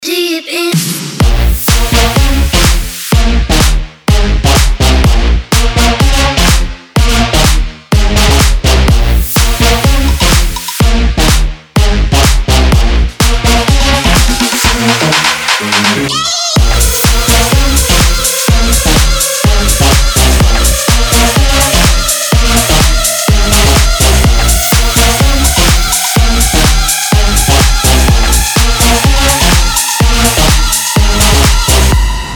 • Качество: 320, Stereo
громкие
EDM
энергичные
Big Room
Заводной big room